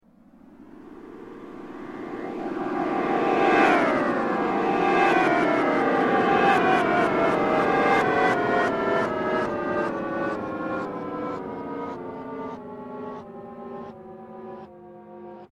描述：抬高它，得到一个摇摆不定
Tag: 88 bpm Weird Loops Bass Loops 939.74 KB wav Key : A